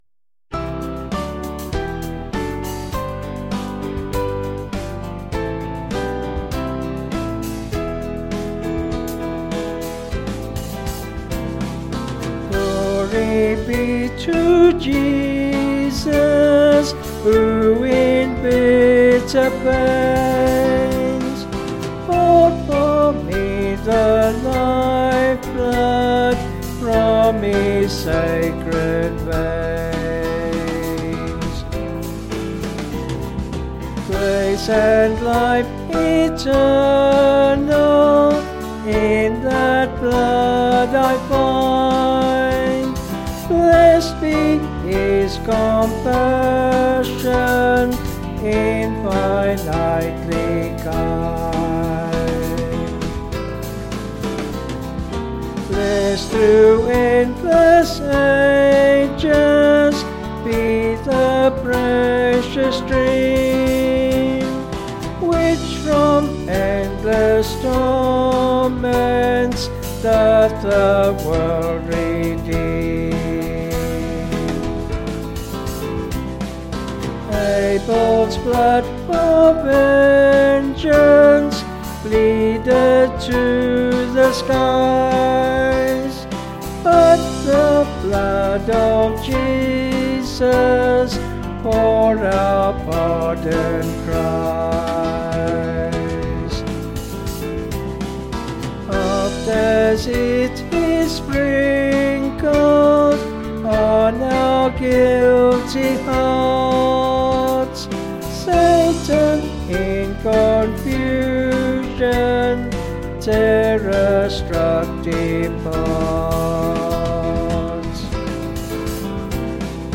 Vocals and Band   264.2kb Sung Lyrics